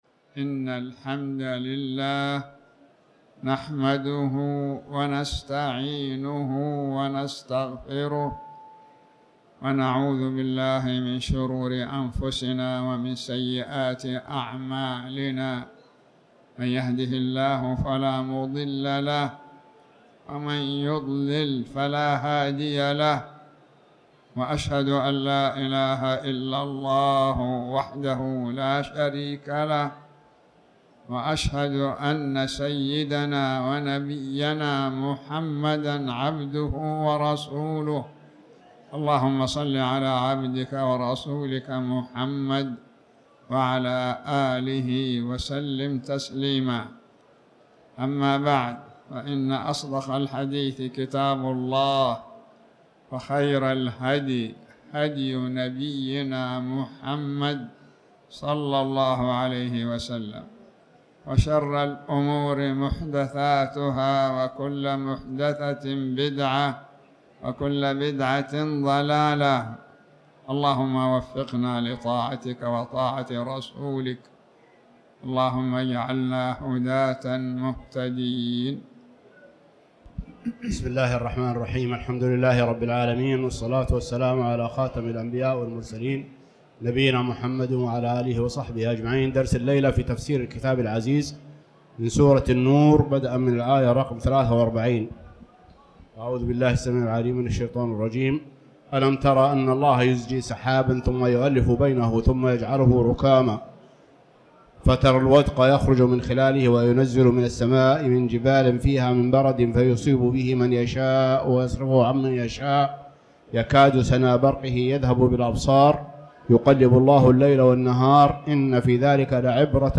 تاريخ النشر ١٠ جمادى الأولى ١٤٤٠ هـ المكان: المسجد الحرام الشيخ